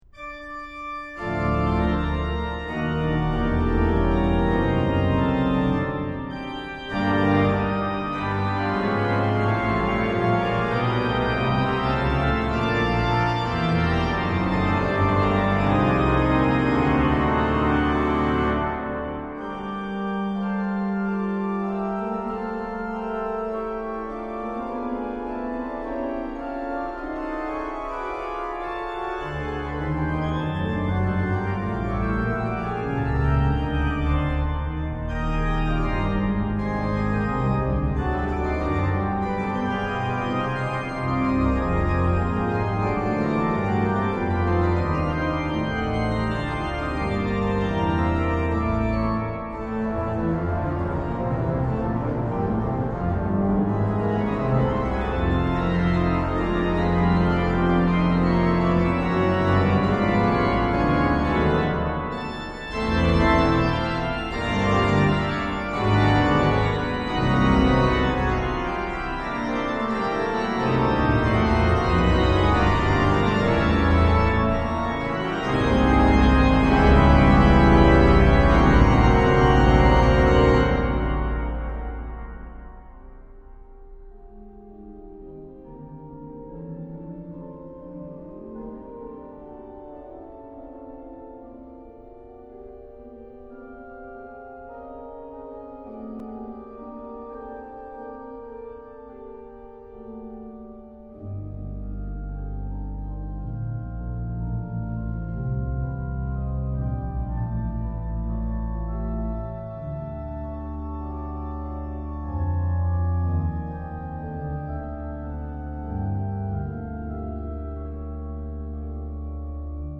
Konzertmitschnitt vom 27.11.2005 aus der Johanneskirche Saarbrücken (Orgel: Kleuker/1959/3 Man/mechanisch)